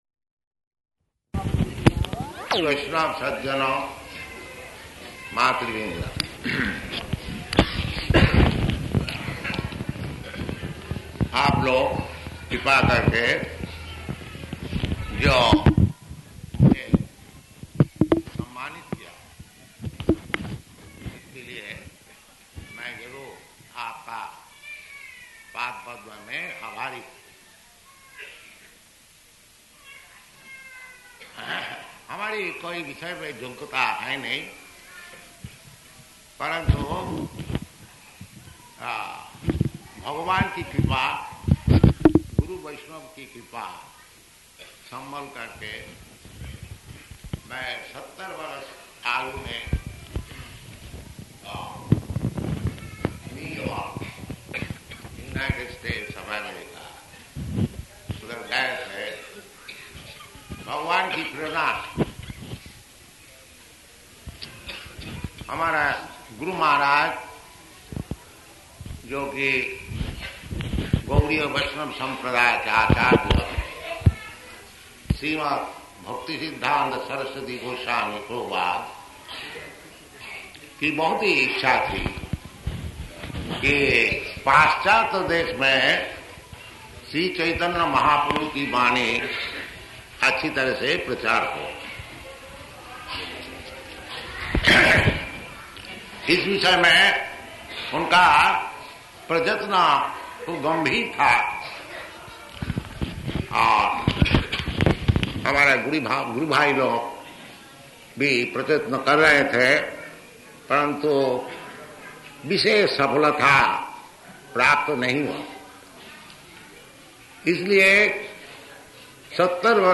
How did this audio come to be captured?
Location: Vṛndāvana